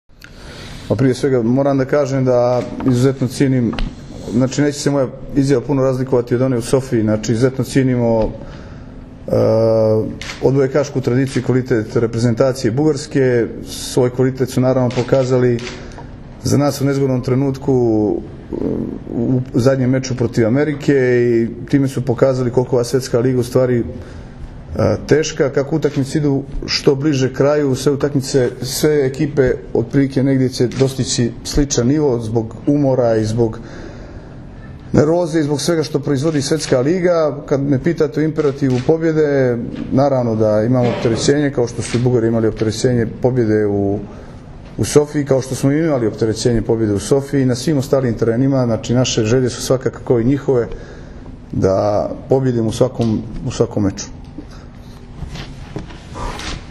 Tim povodom, danas je hotelu “Sole mio” u Novom sadu održana konferencija za novinare, kojoj su prisustvovali Dragan Stanković, Todor Aleksijev, Igor Kolaković i Kamilo Plaći, kapiteni i treneri Srbije i Bugarske.
IZJAVA IGORA KOLAKOVIĆA